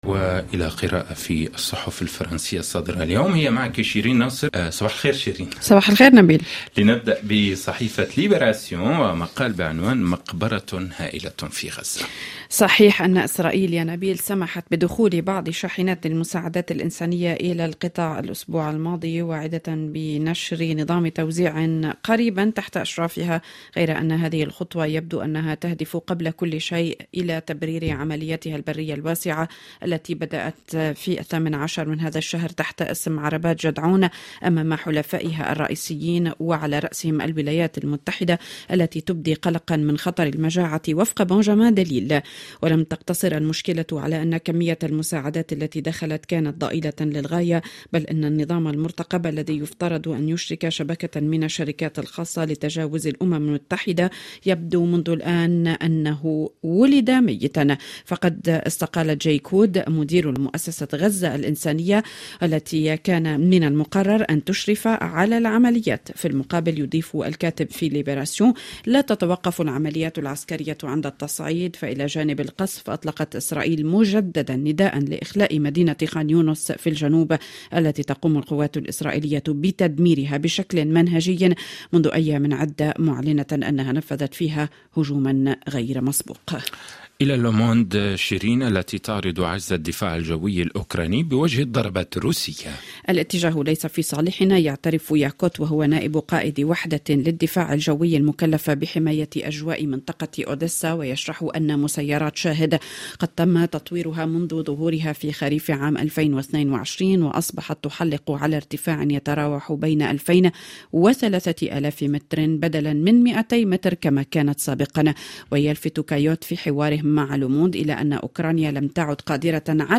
ما لم تقرؤوه في صحف الصباح تستمعون إليه عبر أثير "مونت كارلو الدولية" في عرض يومي صباحي لأهم التعليقات والتحليلات لكل قضايا الساعة في فرنسا والعالم العربي والعالم وحازت على اهتمام الصحف الفرنسية.